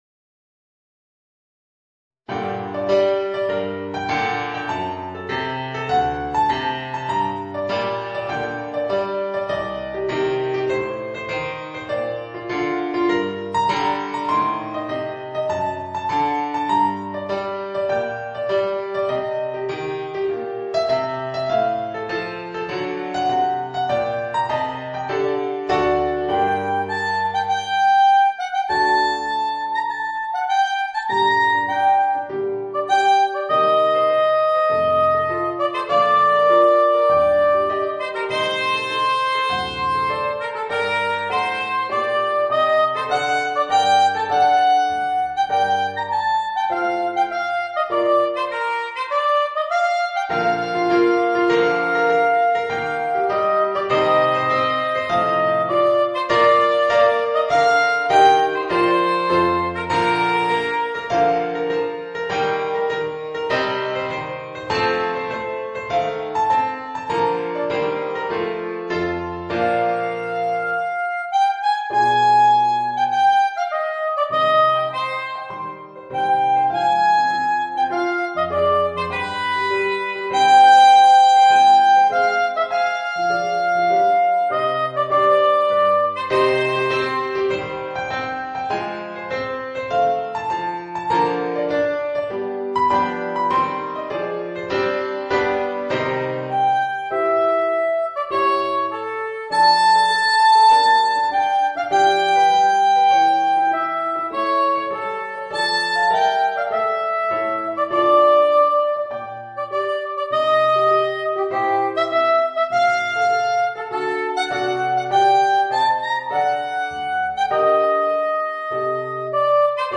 Voicing: Soprano Saxophone and Organ